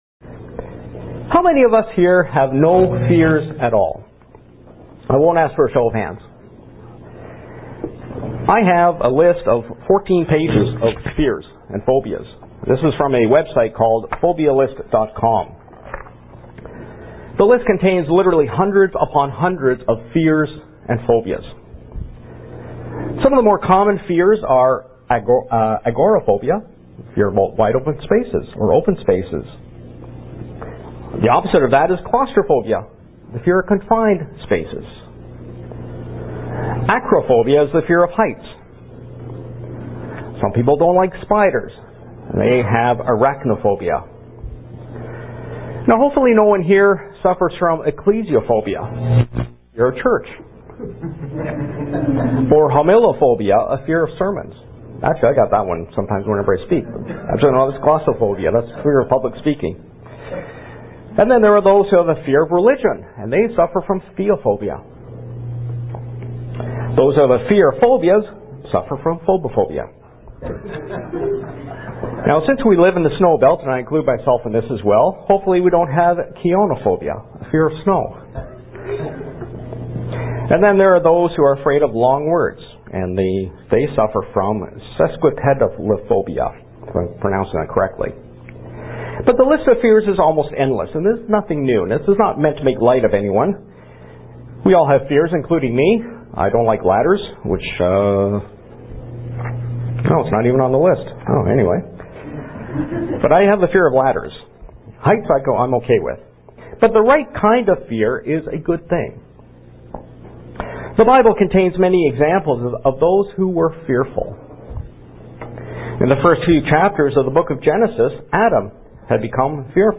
UCG Sermon Studying the bible?